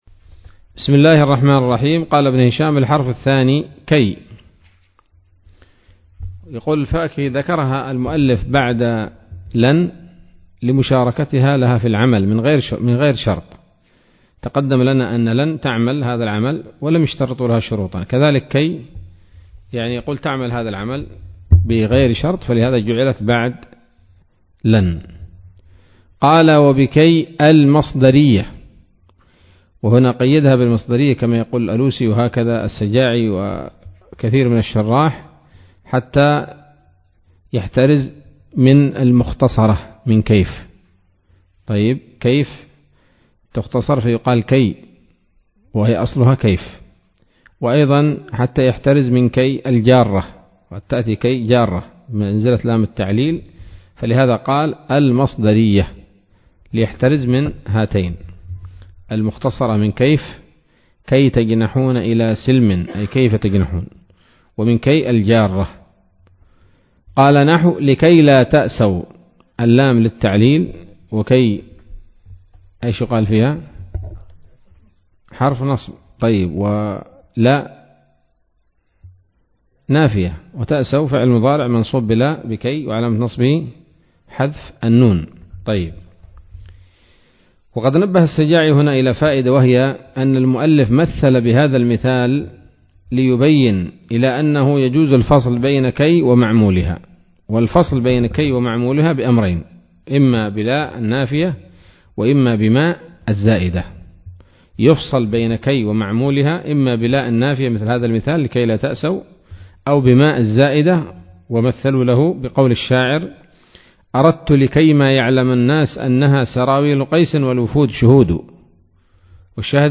الدرس السابع والعشرون من شرح قطر الندى وبل الصدى [1444هـ]